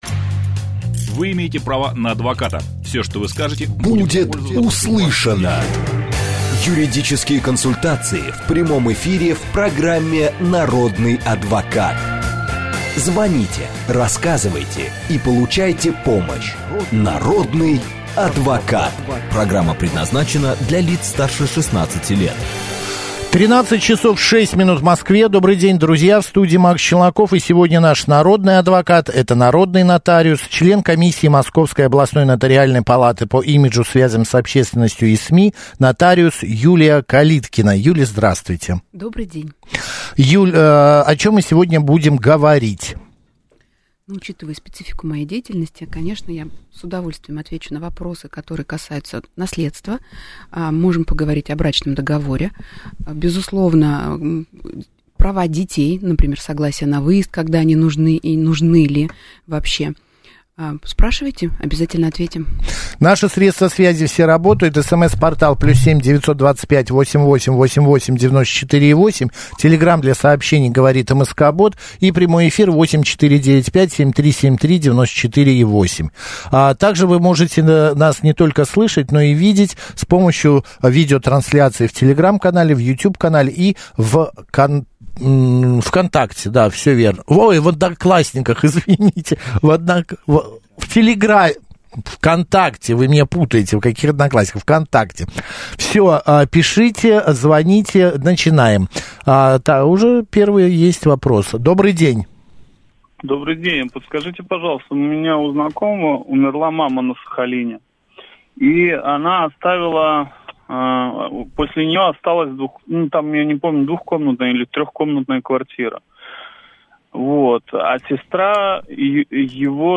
Нотариус в радиоэфире: что важно знать об оформлении наследства
Этот момент стал поводом для переживаний радиослушательницы, которая задала нотариусу вопрос, как себя уберечь от кредитов пожилого отца в случае его смерти.